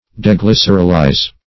deglycerolize - definition of deglycerolize - synonyms, pronunciation, spelling from Free Dictionary
deglycerolize.mp3